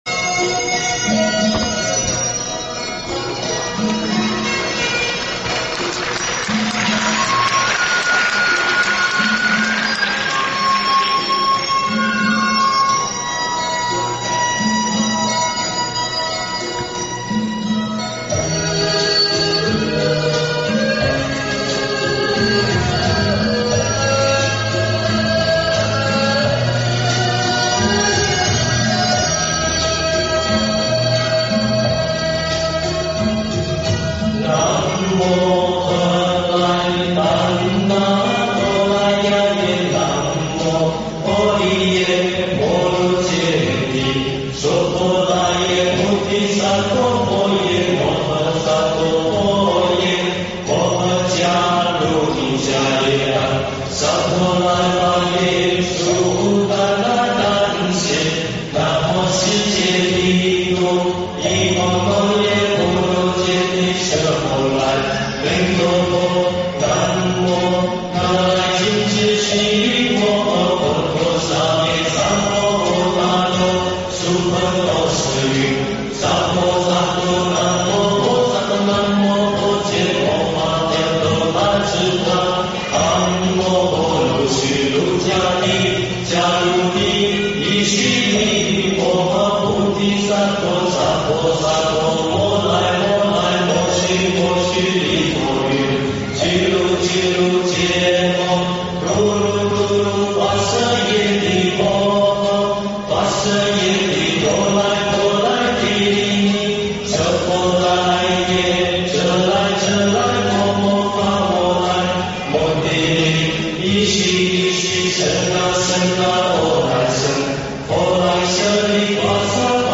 音频：法国巴黎观音堂的年轻同修、在联合国2018年卫塞节上演唱的佛歌《大悲咒》！天籁之音、震撼人心、法喜充满